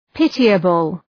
pitiable.mp3